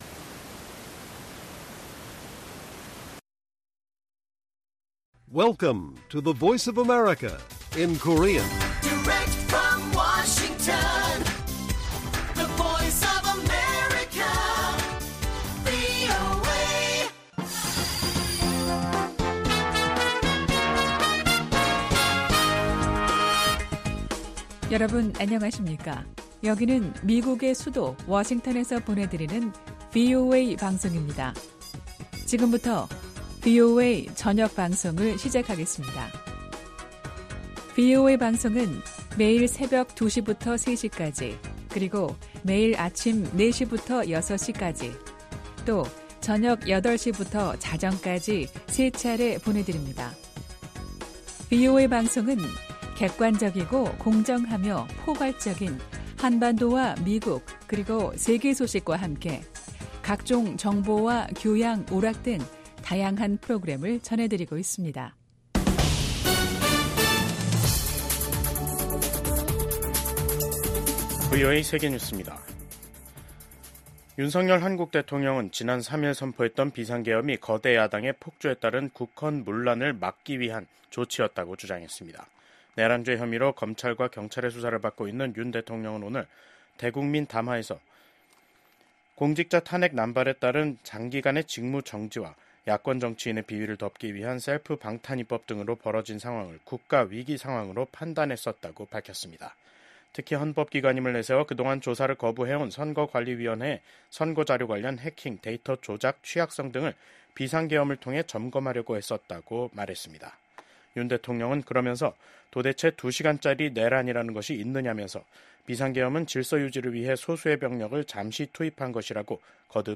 VOA 한국어 간판 뉴스 프로그램 '뉴스 투데이', 2024년 12월 12일 1부 방송입니다. 비상계엄 사태로 내란죄 혐의를 받고 있는 윤석열 한국 대통령은 담화를 통해 계엄령 선포의 정당성을 주장하면서 자진사퇴를 거부했습니다. 미국 국방부는 북한이 한국의 혼란한 정치 상황을 오판할 가능성에 대해 경고했습니다. 미국 민주당 상원의원들은 윤석열 한국 대통령 탄핵 사유로 외교 정책이 거론된 것과 관련해 미한일 3국 협력의 중요성을 강조했습니다.